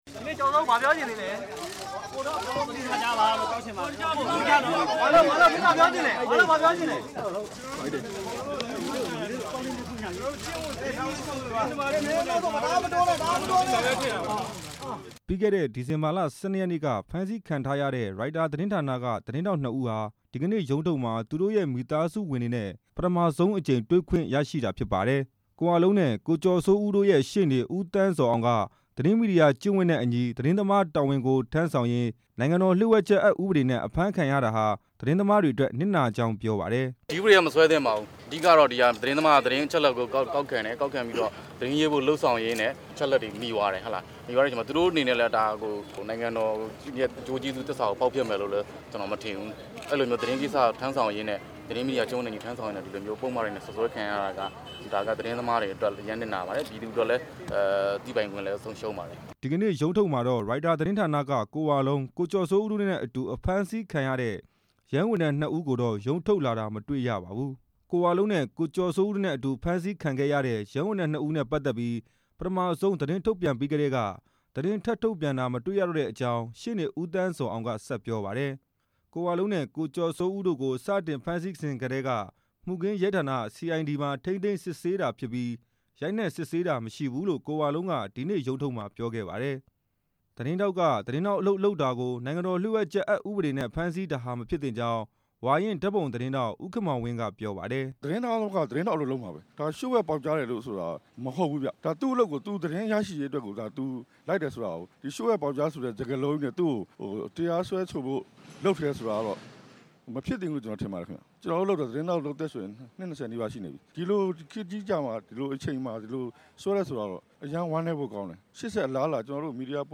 ရုံးထုတ်ကို လာရောက်သတင်းယူကြတဲ့ သတင်းသမားတွေရဲ့အသံကို